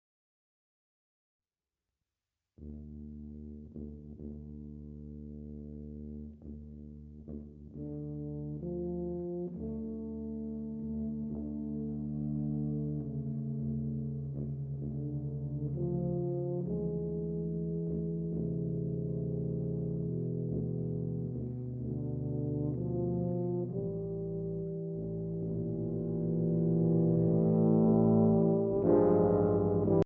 eine mit Swingphrasierung zu spielende langsame Ballade